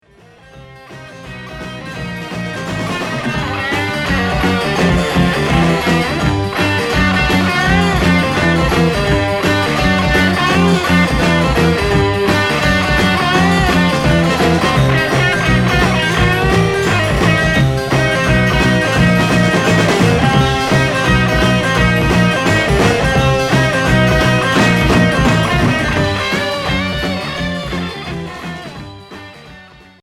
Heavy rock Unique 45t retour à l'accueil